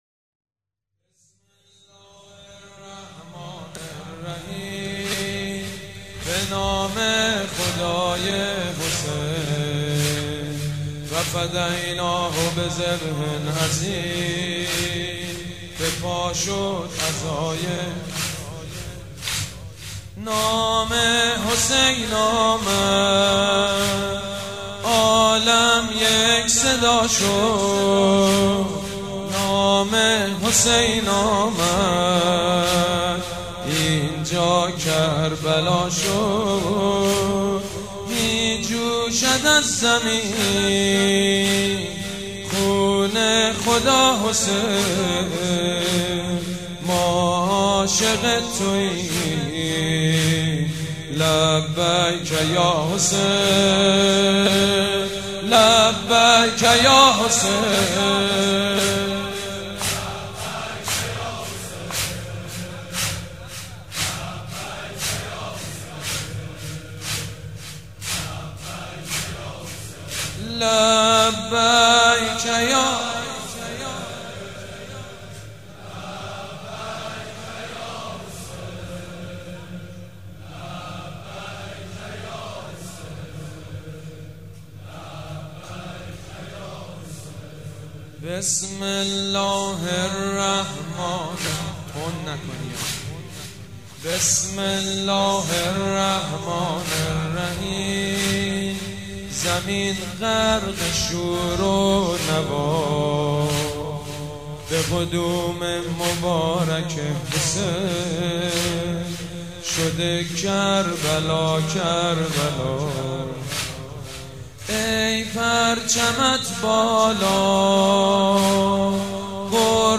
نوحه
مراسم عزاداری شب دوم